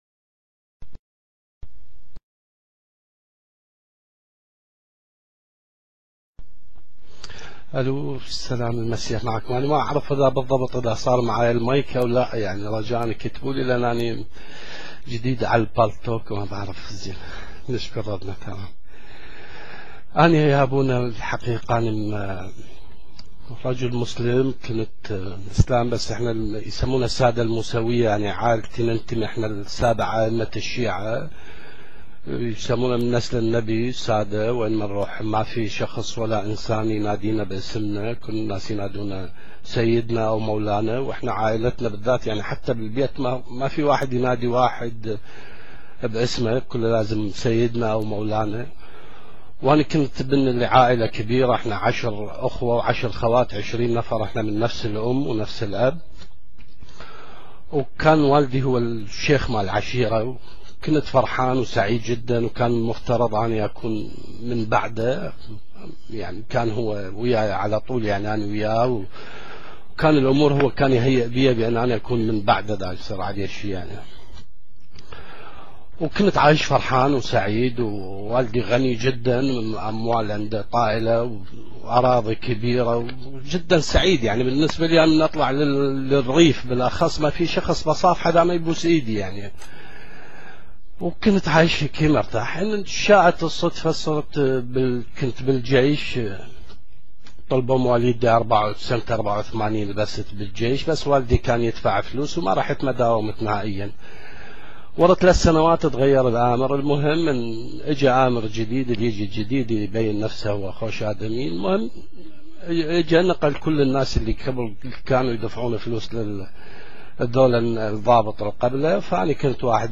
فأجرى معه حوارا طويلا